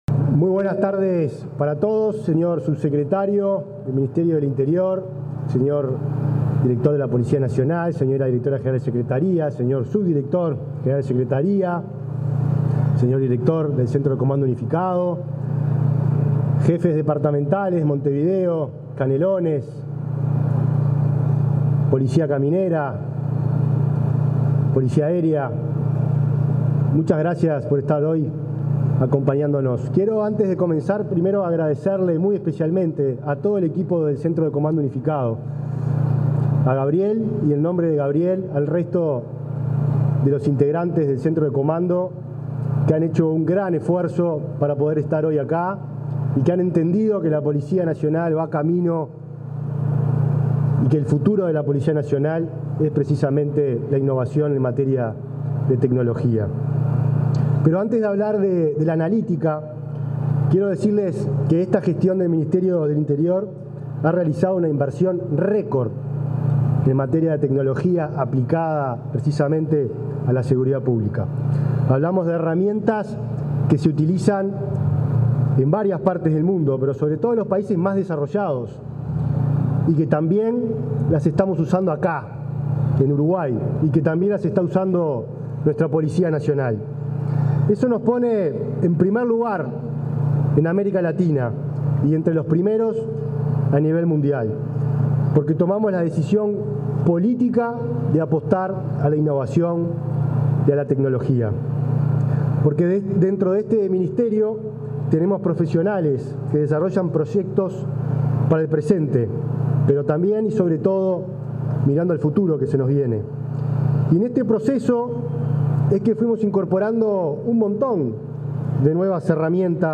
Palabras del ministro del Interior, Nicolás Martinelli
En el marco de la presentación de nueva tecnología para cámaras de videovigilancia, se expresó el ministro del Interior, Nicolás Martinelli